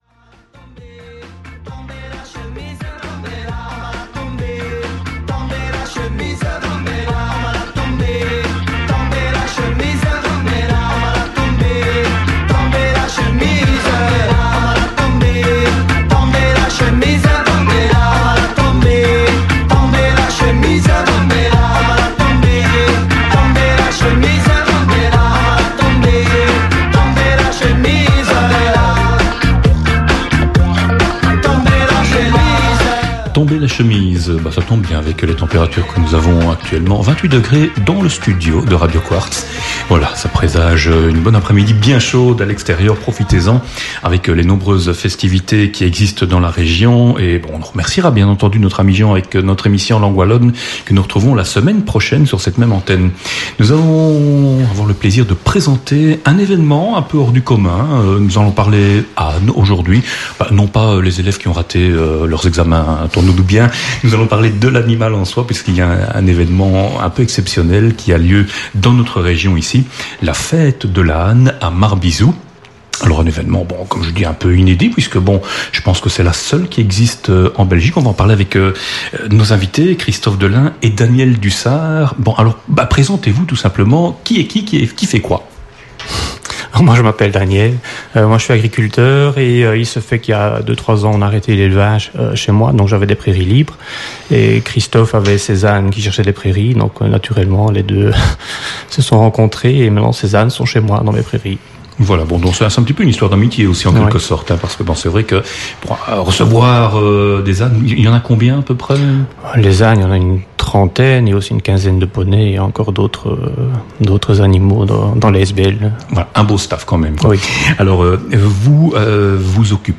Retrouvez ici le contenu de cette interview :